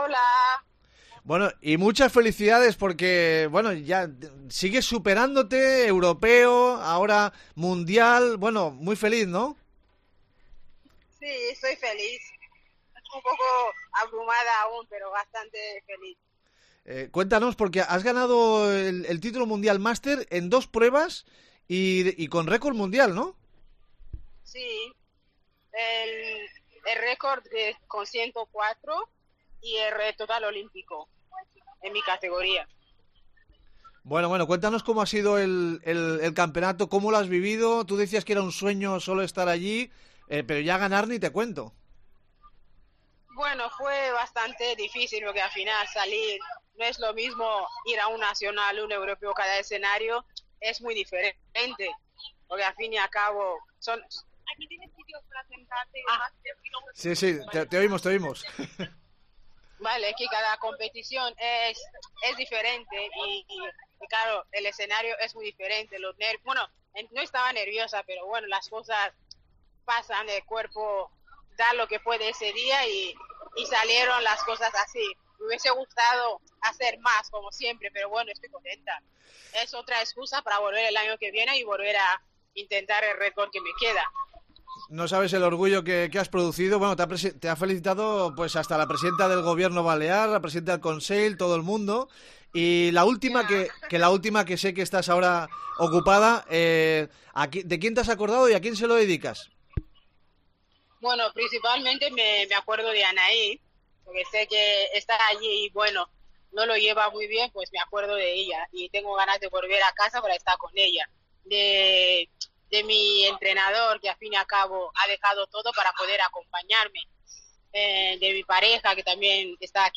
nos atiene en directo desde Orlando